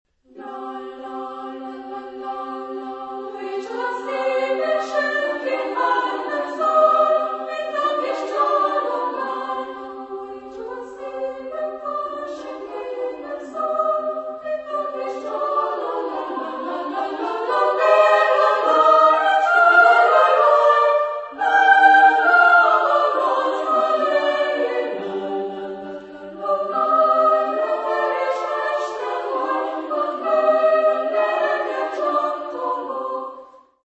Genre-Style-Form: Partsong
Mood of the piece: fast
Type of Choir: SSA  (3 women voices )
Instruments: Bass drum (Ad lib)
Tonality: C minor